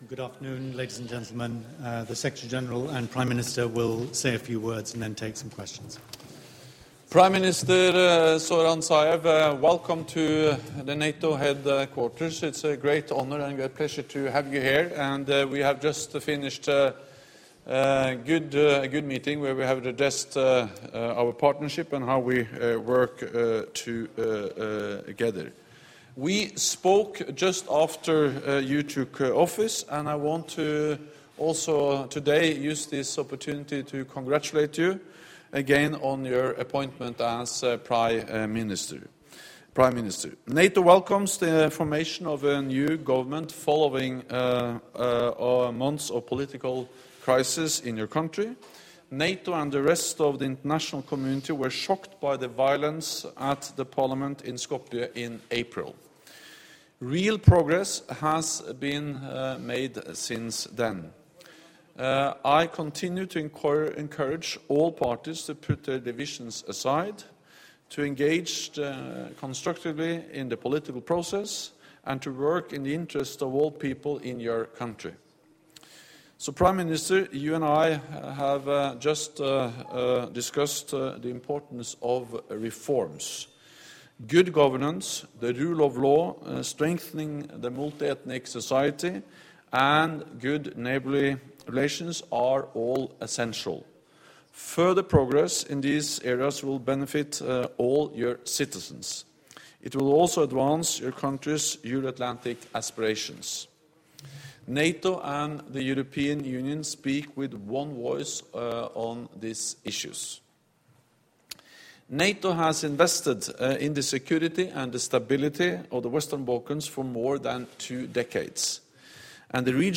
(As delivered)
ENGLISH - Joint press point with NATO Secretary General Jens Stoltenberg and Zoran Zaev, Prime Minister of the former Yugoslav Republic of Macedonia¹